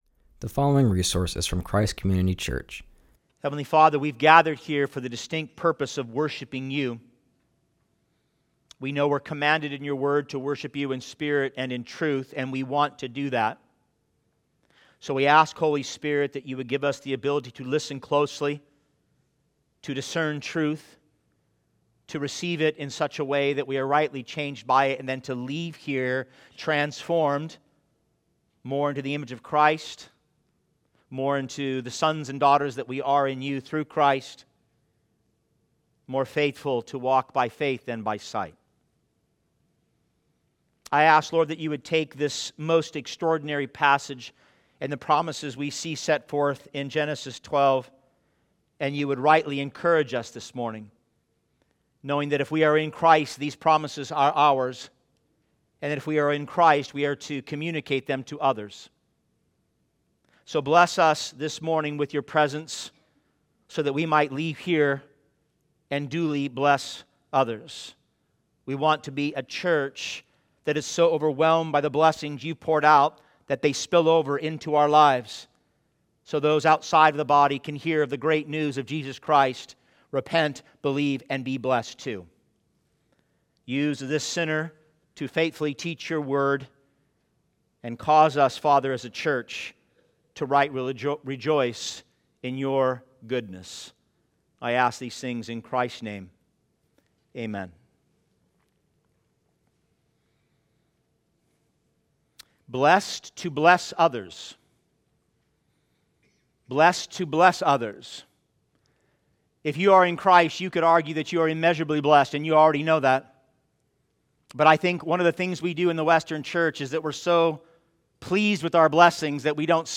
preaches from Genesis 11:10-12:3.